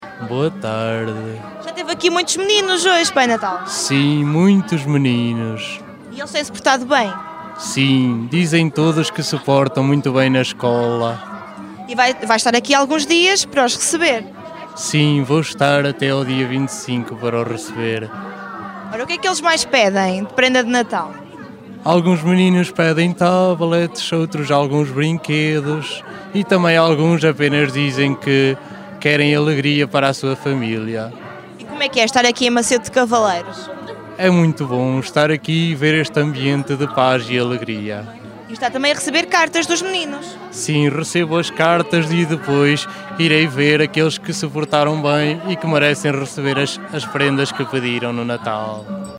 E nesta quadra de luz e de magia, conseguimos uma entrevista com a figura mais requisitada por estes dias, o Pai Natal.